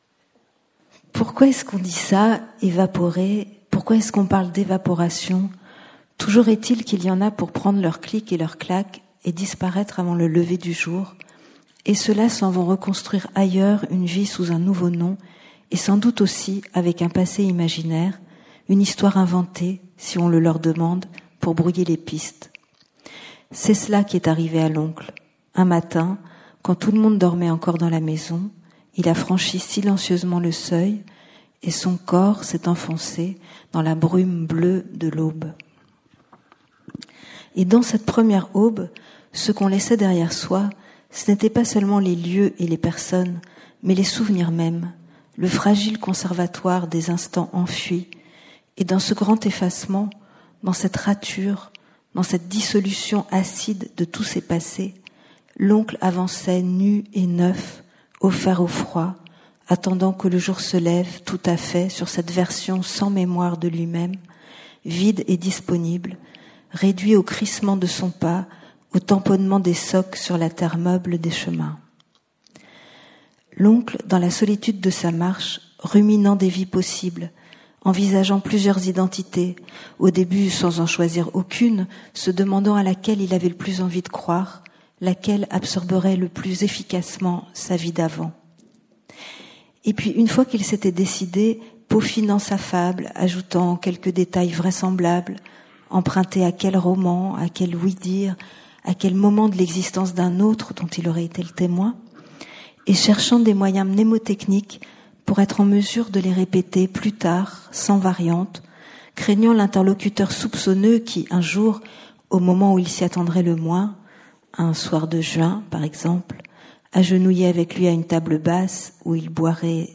Lecture à l’occasion de la soirée Poésie Fiction 2014 organisé par la Maison de la poésie de Rennes et la Bibliothèque des Champs Libres.
Prise de son et mixage, Radio Univers.